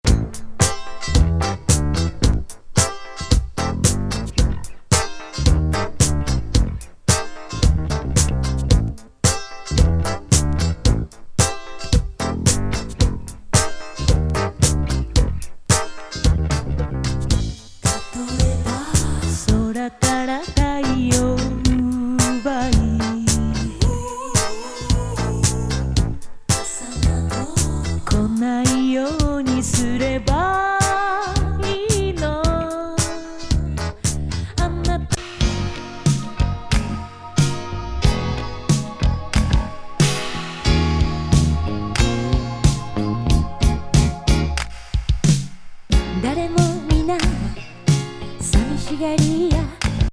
メロウAOR